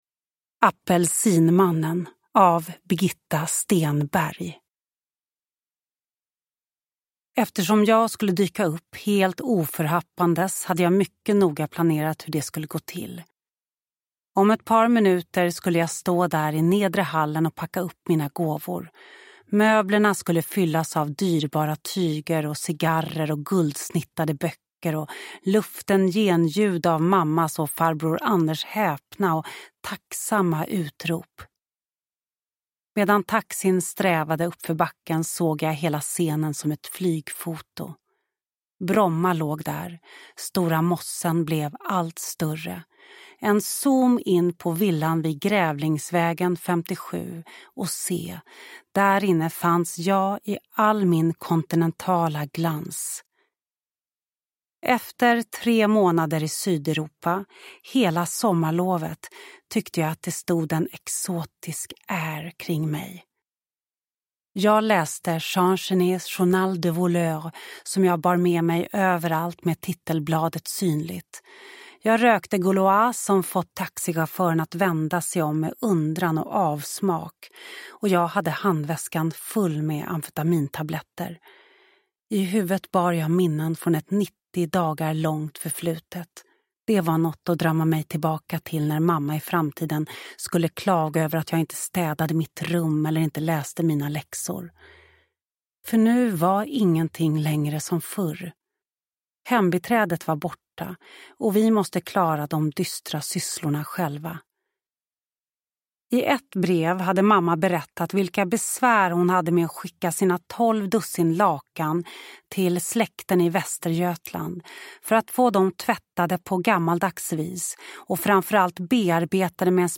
Apelsinmannen – Ljudbok – Laddas ner
Uppläsare: Lo Kauppi